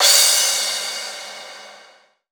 Chart Cymbal 01.wav